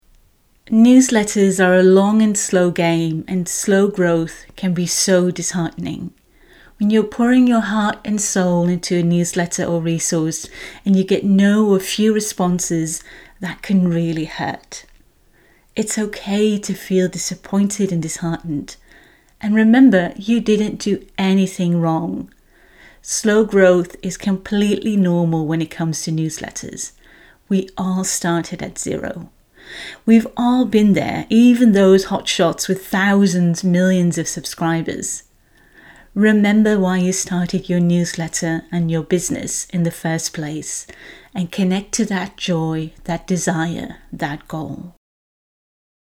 🎧 And as a special bonus I created five mini audio peptalks for the course to give you that extra boost of support when you need it:
• a peptalk when you’re feeling disheartened or growth is slow